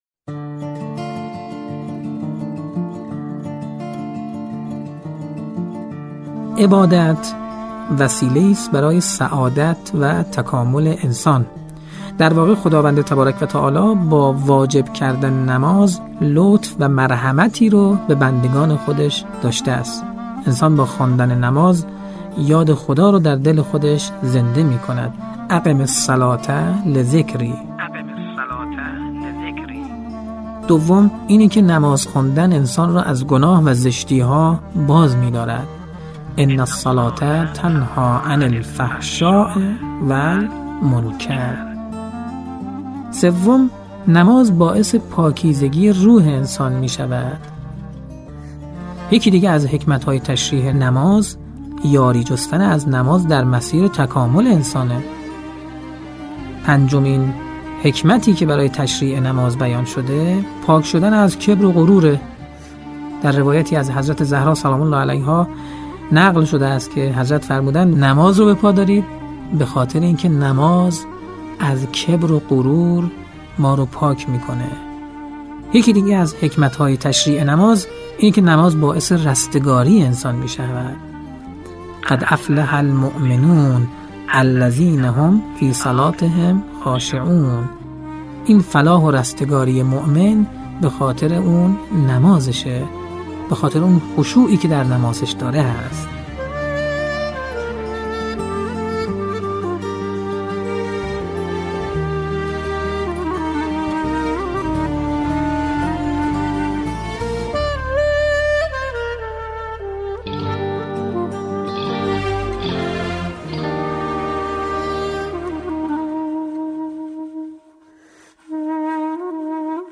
نواهنگ